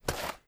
High Quality Footsteps
STEPS Dirt, Walk 06.wav